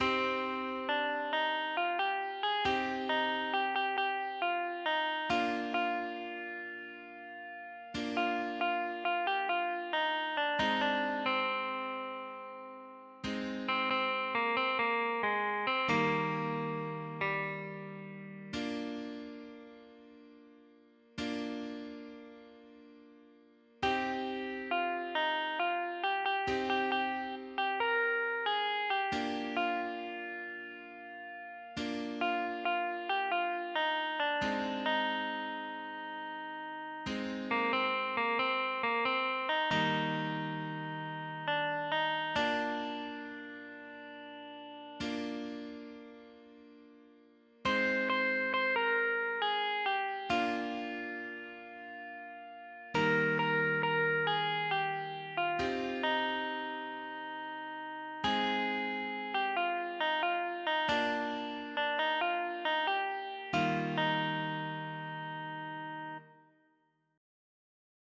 (Via Crucis cantado)